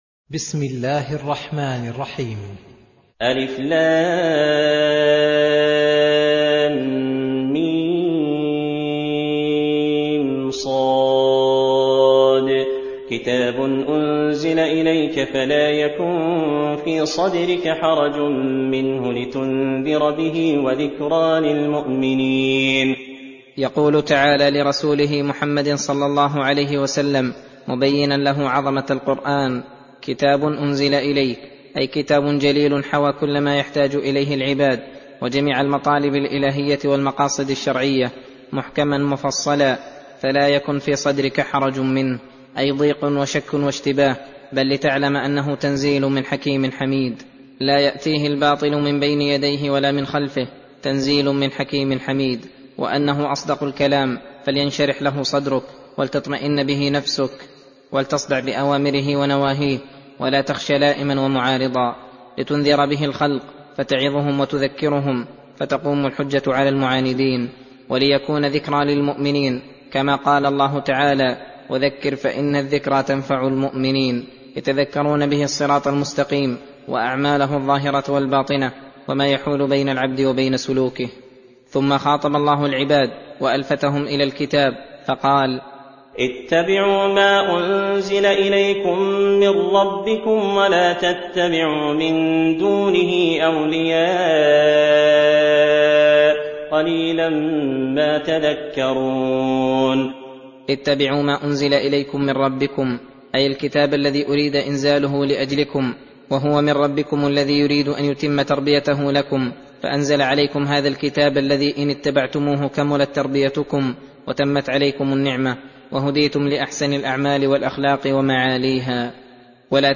درس (1) تفسير سورة الأعراف : (1-23)